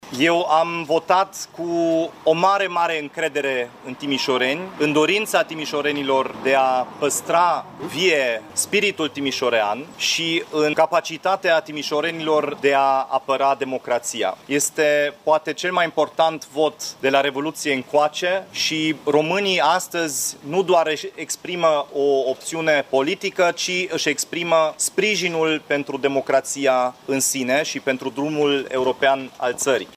Zece candidați s-au înscris în cursa pentru primăria Timișoarei. Actualul edil, Dominic Fritz, care candidează pentru un nou mandat din partea USR, a votat după ora 12.00: